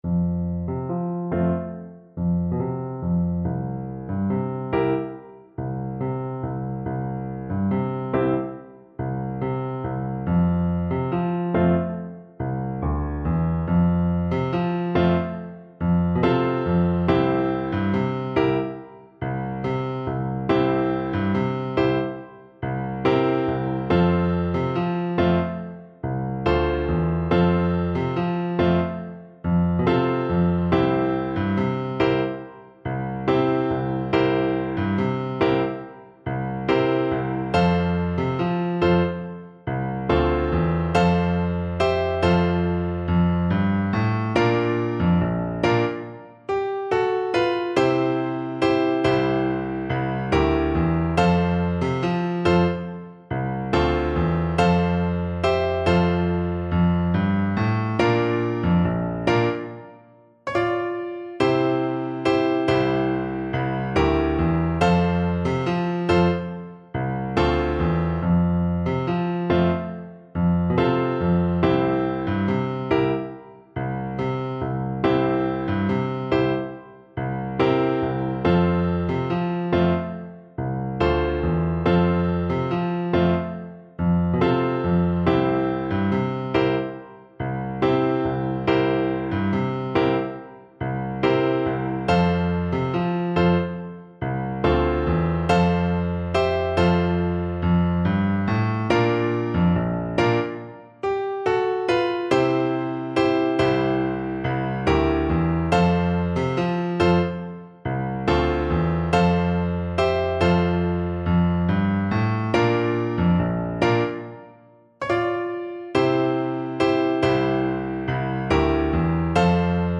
Flute version
Flute
A traditional folk song from Kenya
F major (Sounding Pitch) (View more F major Music for Flute )
4/4 (View more 4/4 Music)
Allegro =c.110 (View more music marked Allegro)
C6-A6
world (View more world Flute Music)